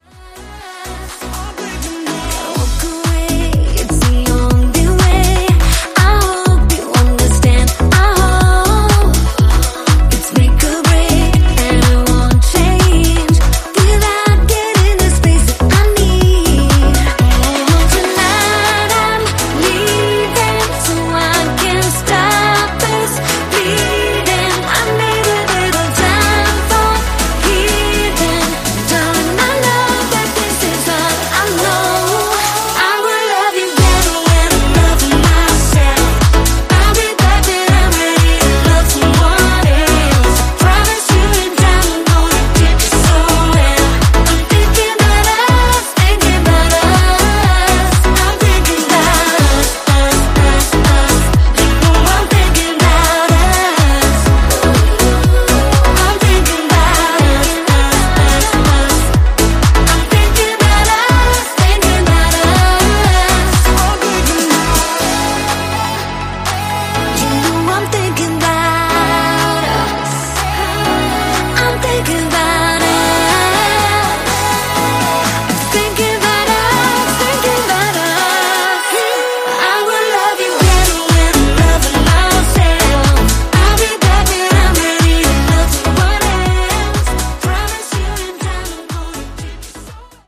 ジャンル(スタイル) POP / HOUSE